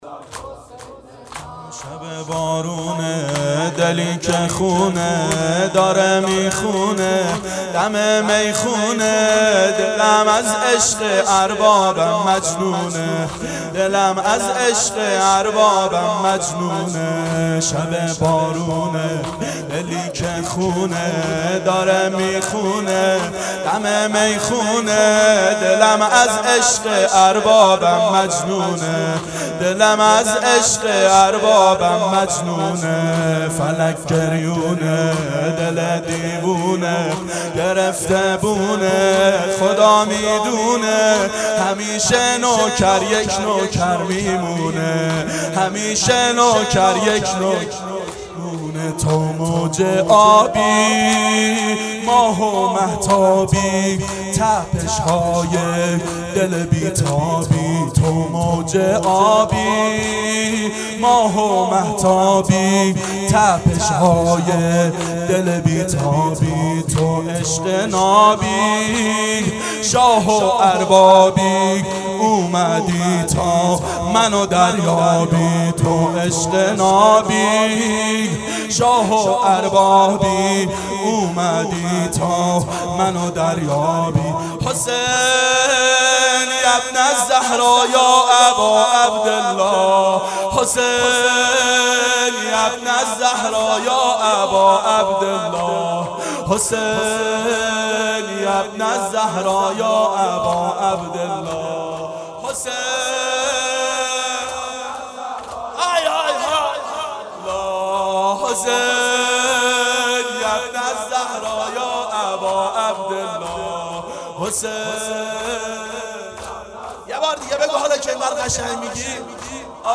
دهه اول صفر سال 1390 هیئت شیفتگان حضرت رقیه س شب اول